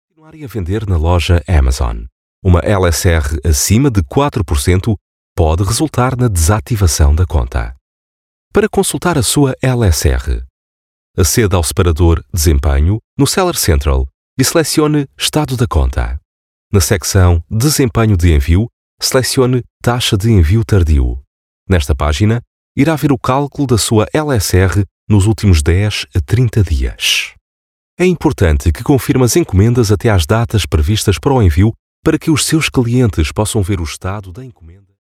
Voice Samples: eLearning
male